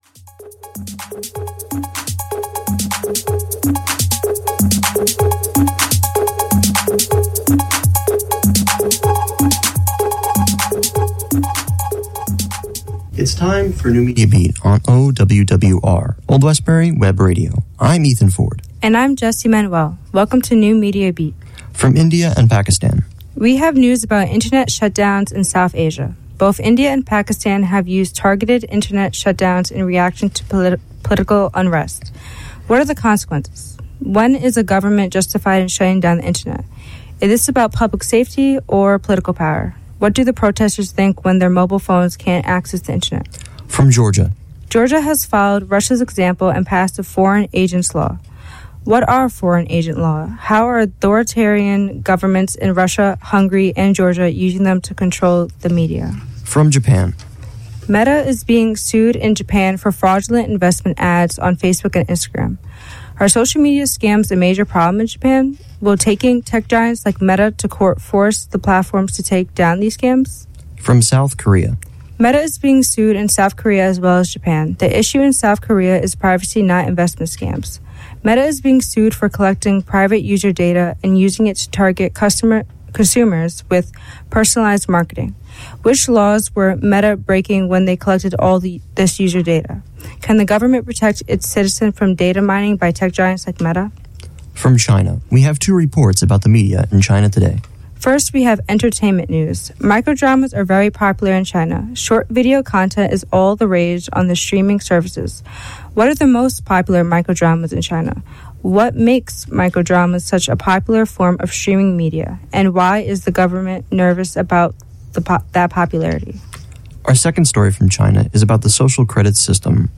The NMB Podcast streams live on Old Westbury Web Radio Thursdays from 10:15-11:15 AM EST. Can’t listen live?